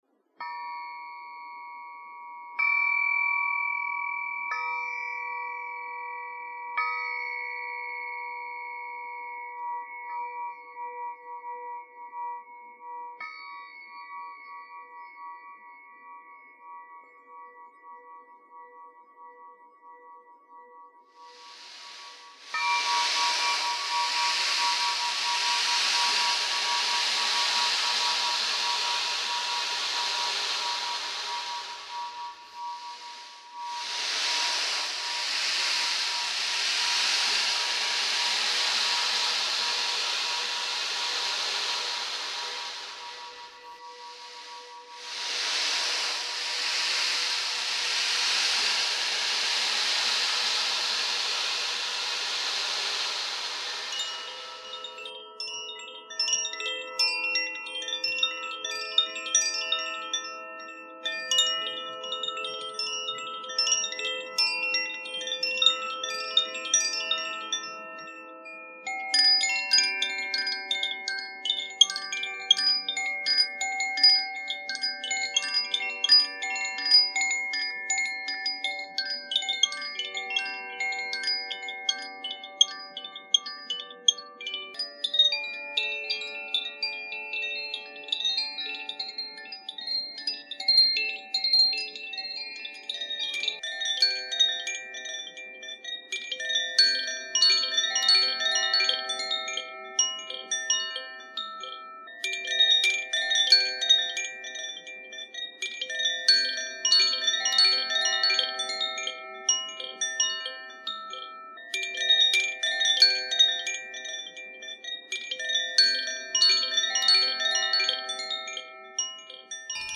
Today they come together in a meditative medley.
6″ Crystal Singing Pyramid
Ocean Drum
Set of 4 Koshi Chimes
Zaphir Blue Moon Chime
Ukulele
Nuvo Clarineo
Nuvo jFlute